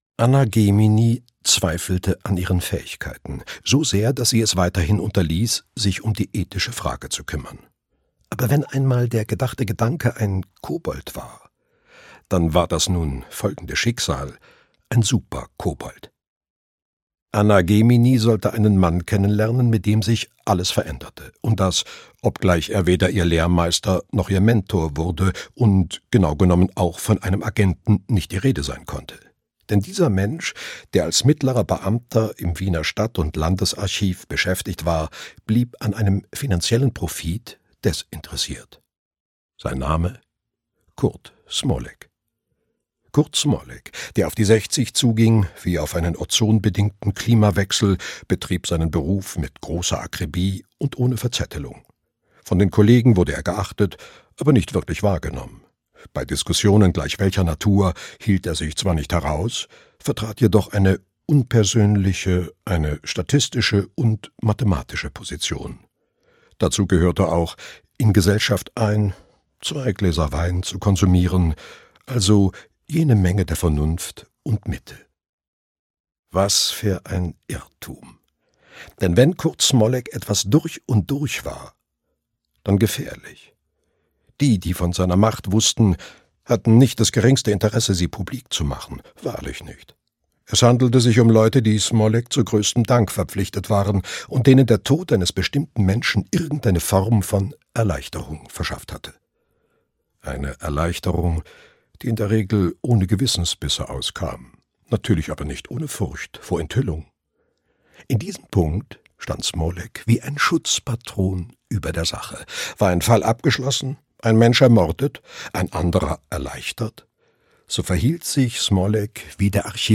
Ein dickes Fell (Markus-Cheng-Reihe 3) - Heinrich Steinfest - Hörbuch